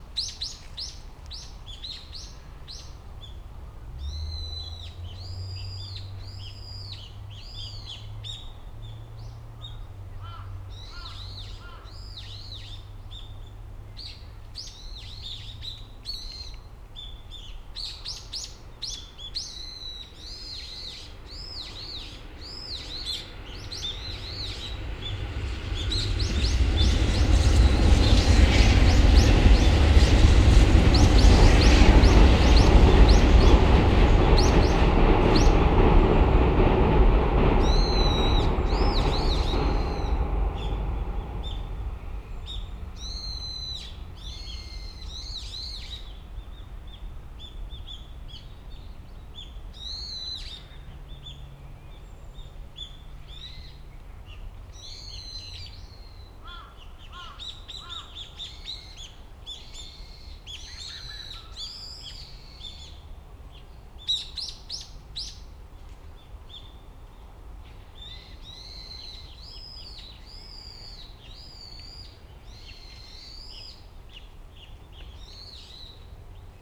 70年代バイノーラルマイクと2018年発売アンビソニックマイクの録音比較テスト
バイノーラル録音は、バイノーラル効果を得るためにヘッドホンで再生してください。
所沢市　滝の城址公園運動場入口付近の武蔵野線高架下そばで録音(2014年1月3日)
レコーダーは、SONY PCM-D100のリニアPCM/96kHz/24bitで録音。
バイノーラルヘッドホン・マイク